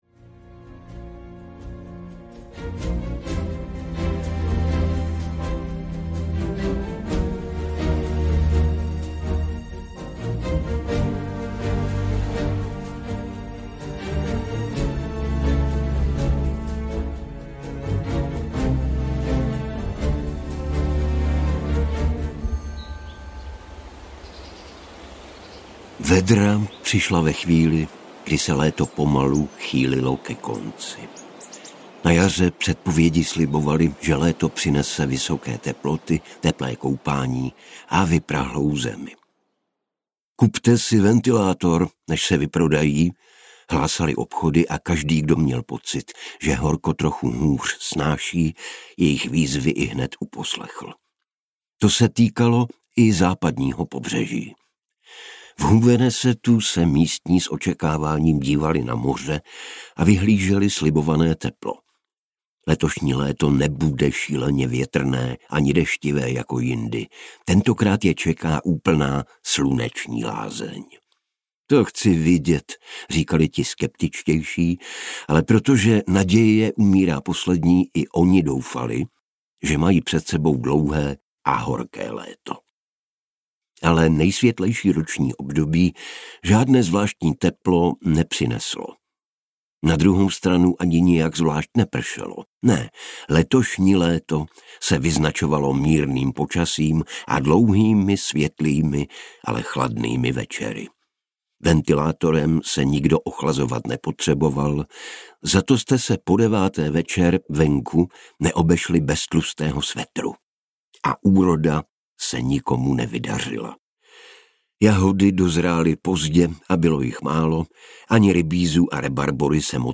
Spása audiokniha
Ukázka z knihy
• InterpretLucie Juřičková, Igor Bareš